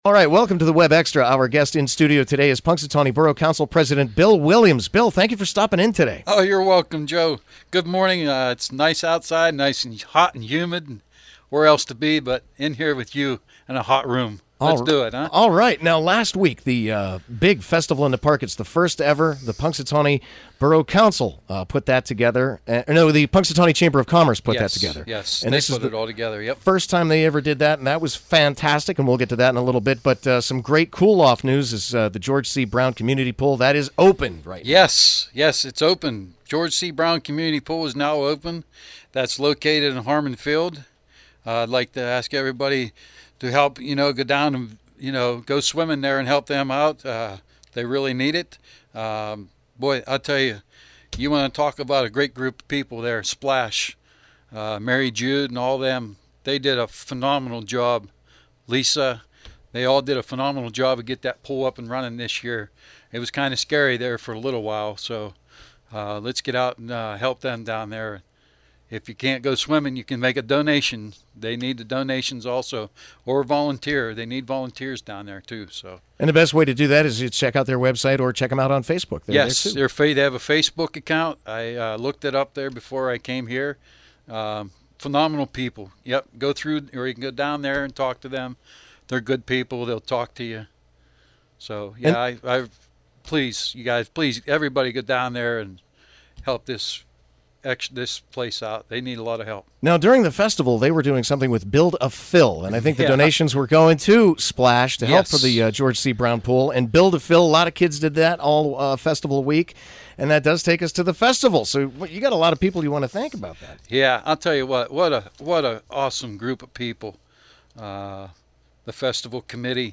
Punxsutawney Borough Council President Bill Williams visited the WPXZ studio to thank everybody who participated in the first ever Festival in the Park.